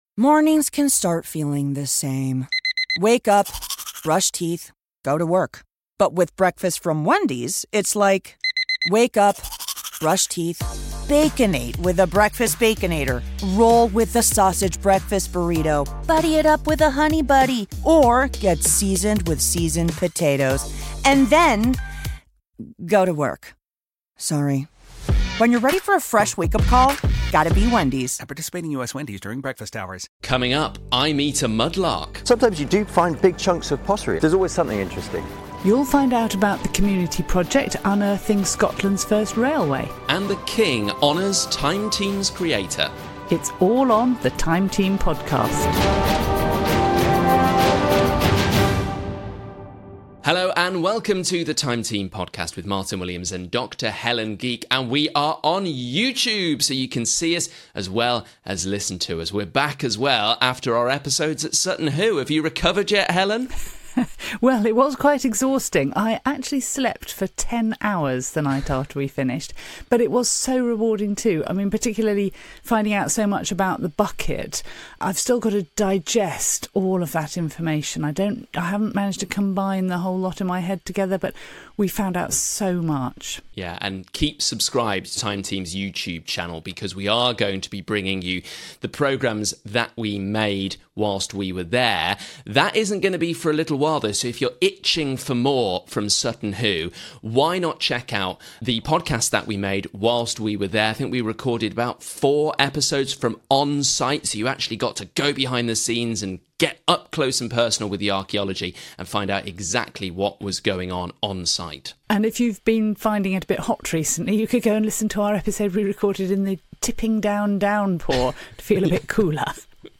Featuring exclusive access to digs and interviews with experts to unearth the stories of the past.